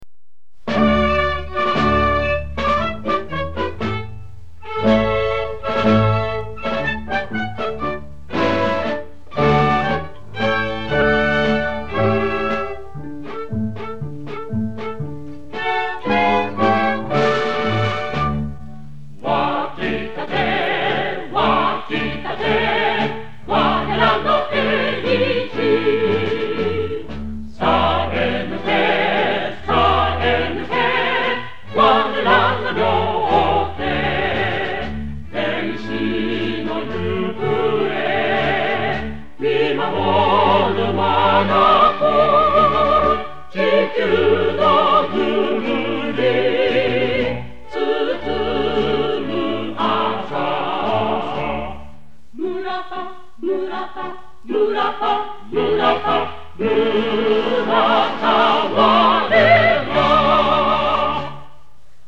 List_R02 最初的社歌《村田吾辈》（1959）
List_R02 第一首公司歌曲（“Our Murata” 1959） 08_2_2_muratawarera.wav ダウンロード